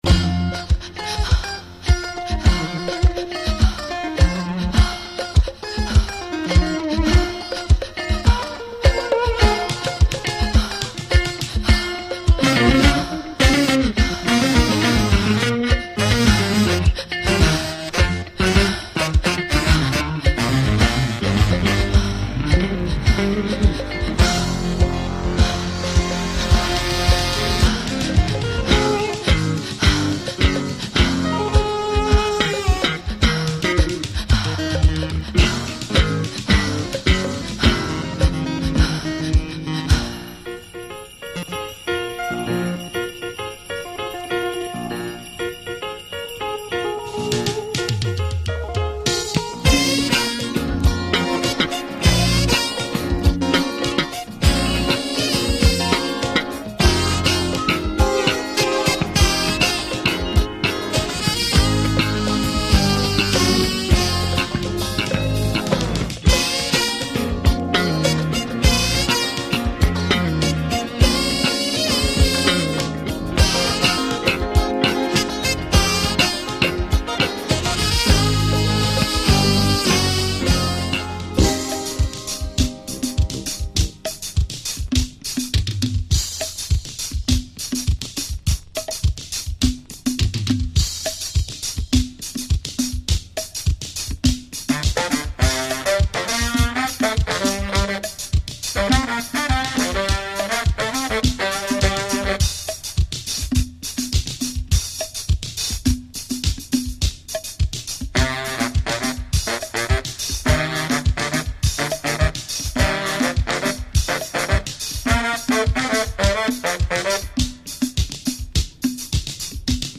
fusion / creole groove album